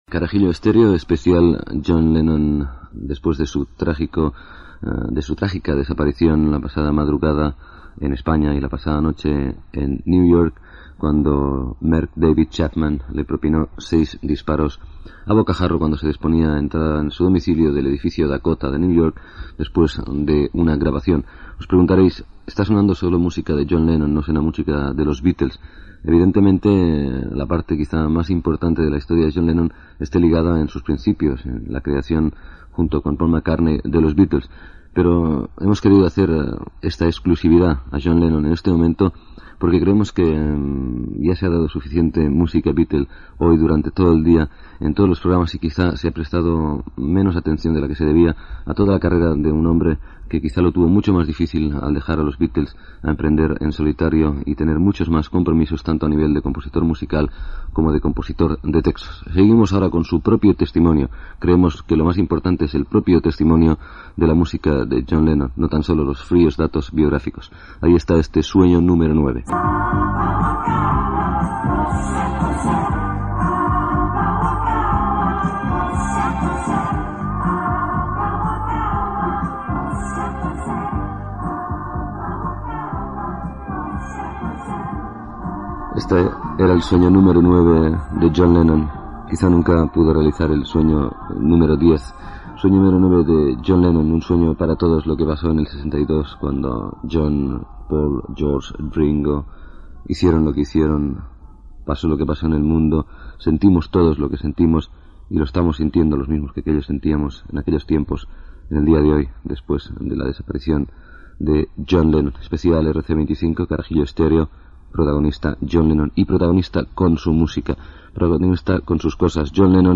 Identificació del programa i fragment de l'espai especial dedicat a John Lennon, assassinat a Nova York el dia anterior
Musical
FM